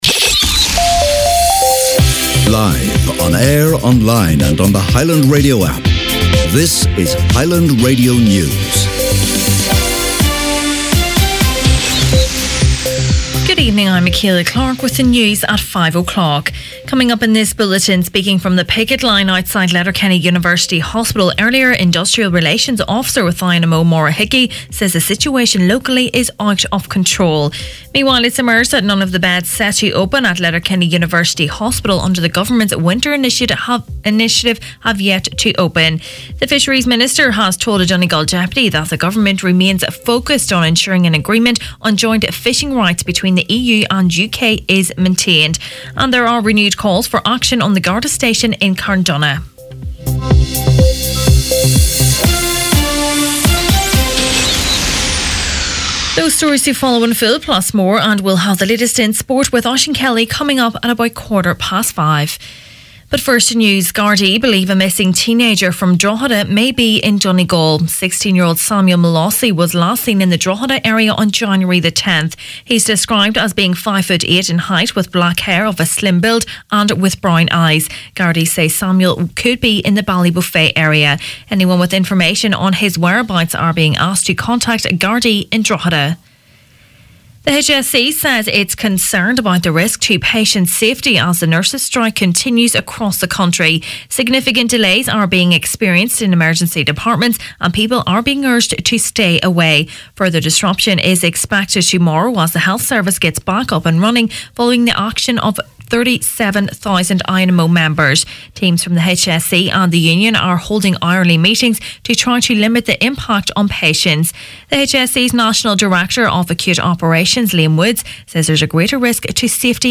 Main Evening News, Sport and Obituaries Wednesday January 30th